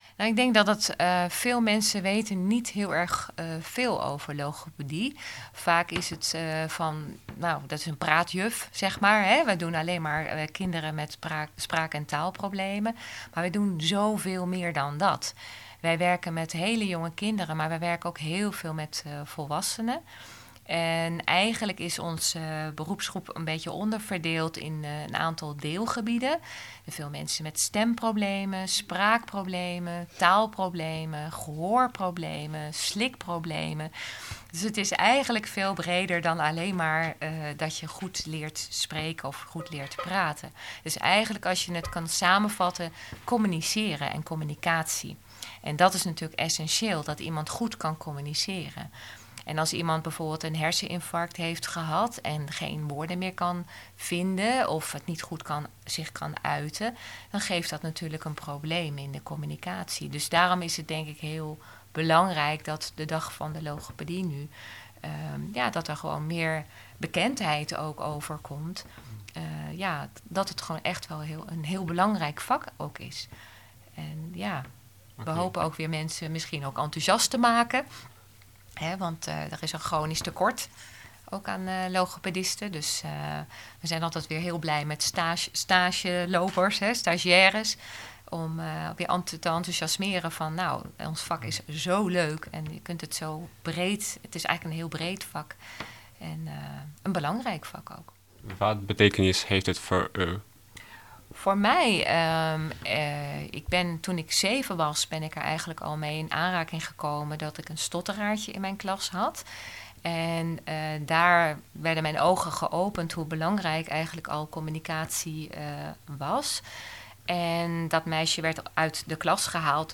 Interview Leiderdorp Maatschappij